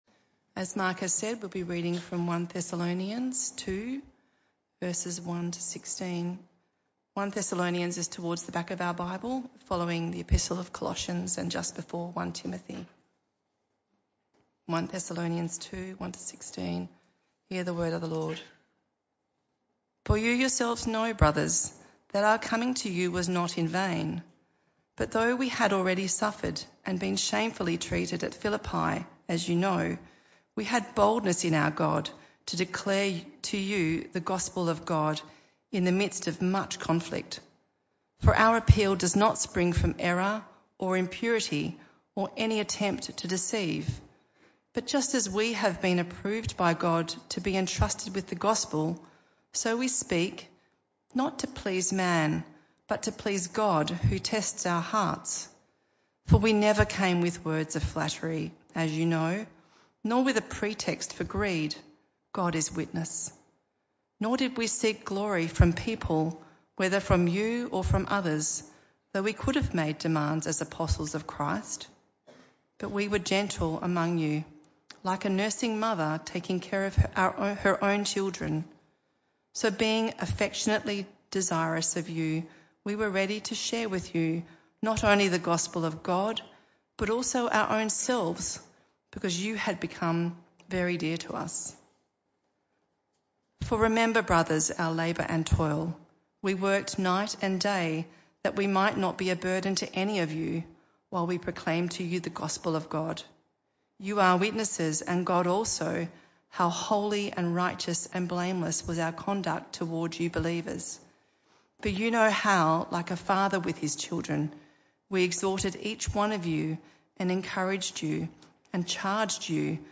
This talk was part of the PM Service series entitled 1 Thessalonians.